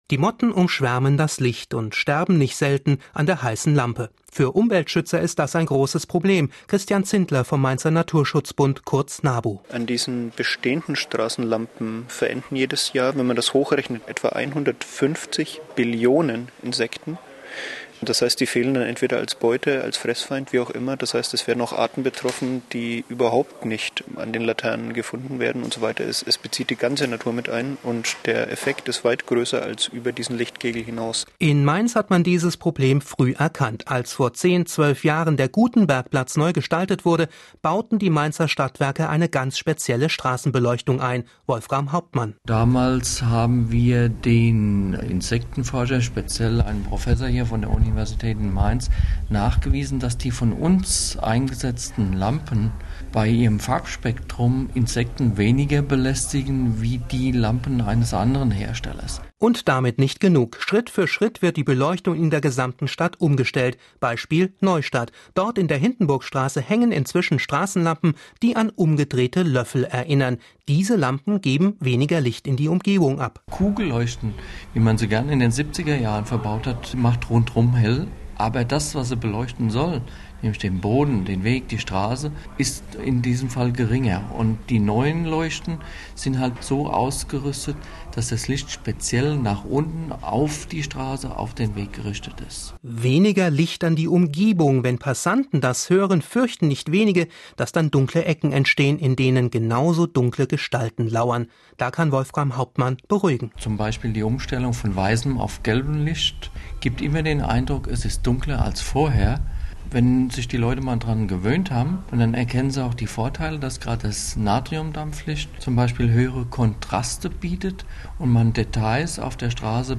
SWR: Radiobeitrag zu Lichtverschmutzung